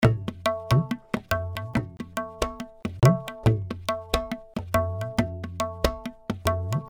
Tabla loops 70 bpm
indian tabla loops in 70 bpm
This is an Indian tabla drum loops (scale A), playing a variety of styles.
Played by a professional tabla player.
The tabla was recorded using one of the best microphone on the market, The AKG C-12 VR microphone. The loops are mono with no EQ, EFFECT or DYNAMICS, but exported stereo for easy Drop and play .